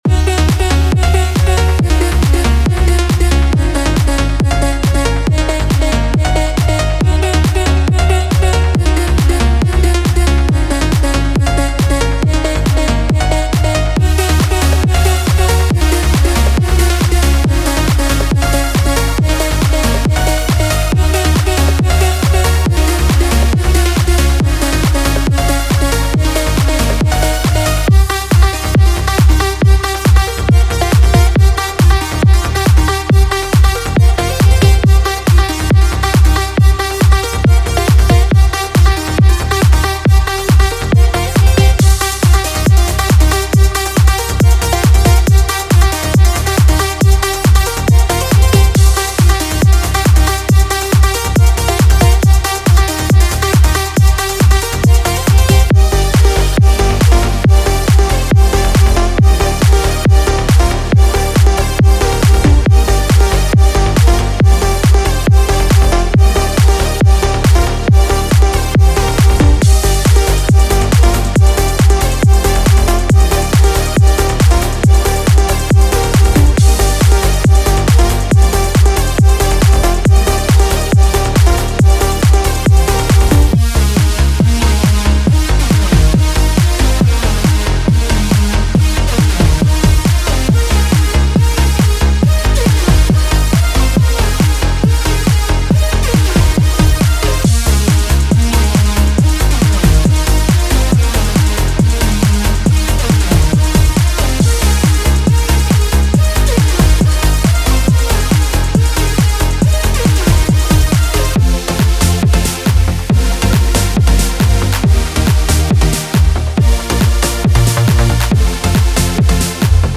This pack includes 91 loops, featuring a diverse range of bass, FX, drums, synth, and vocal loops to supercharge your tracks with vibrant and infectious energy.
Bass Loops: 20 punchy and driving basslines that will keep the dance floor moving.
FX Loops: 15 dynamic and impactful effects to add excitement and transitions to your tracks.
Drum Loops: 25 high-octane drum patterns, from pounding kicks to crisp snares, perfect for creating that classic Eurodance beat.
Synth Loops: 21 uplifting and anthemic synth loops that capture the signature sound of Eurodance with a modern twist.
Vox Loops: 10 powerful and catchy vocal loops that bring energy and memorable hooks to your music.